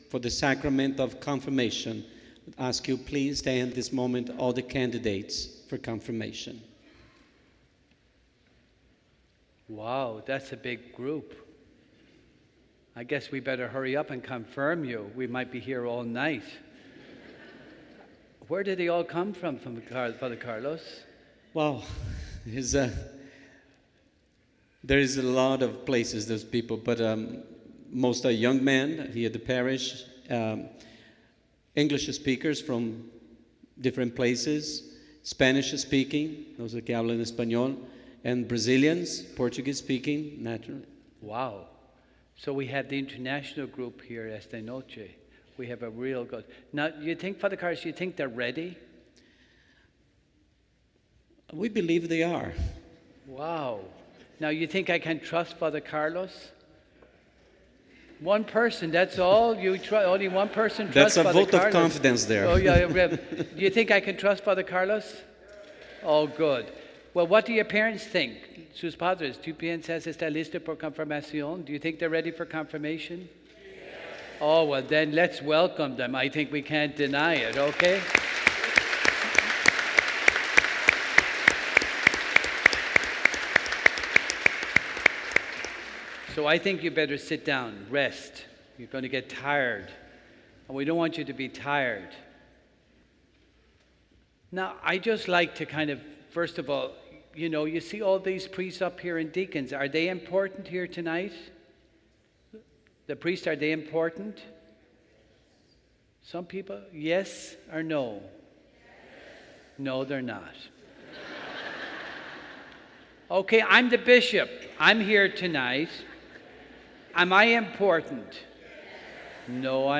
Bishop John Noonan – Confirmation Mass – May 14, 2019